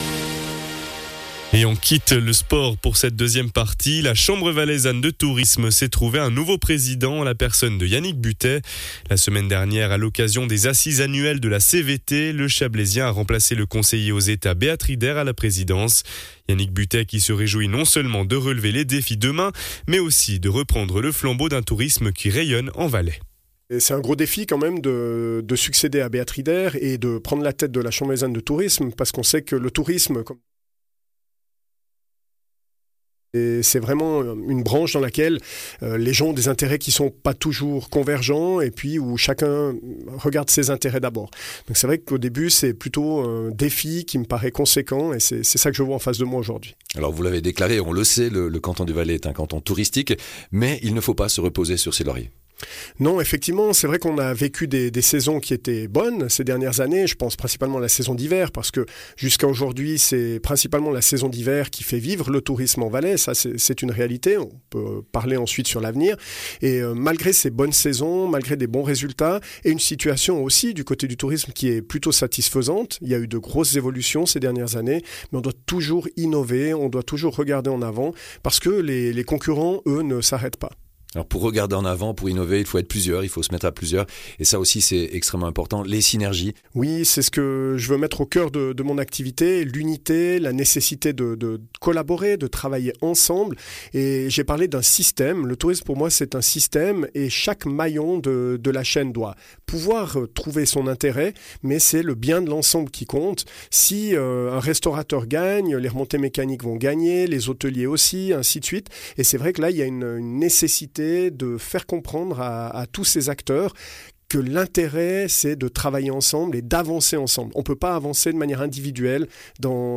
Intervenant(e) : Yannick Buttet, nouveau président de la Chambre Valaisanne de Tourisme